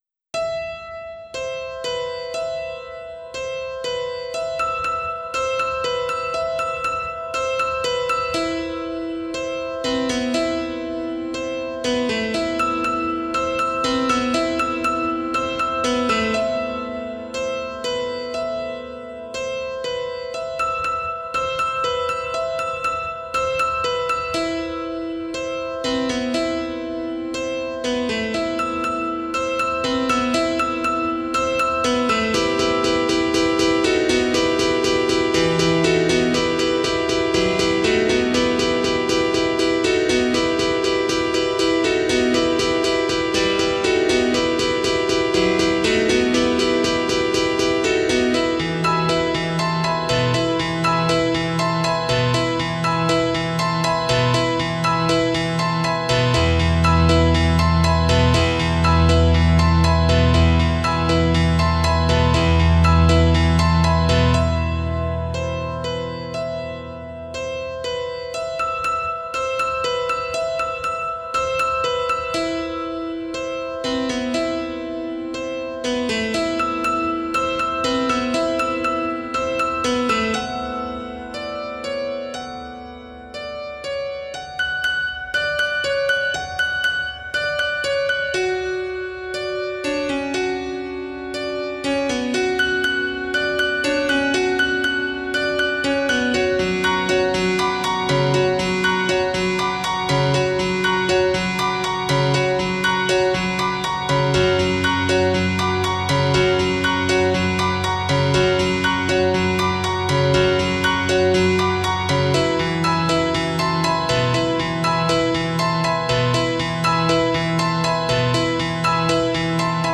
PIANO T-Z (21)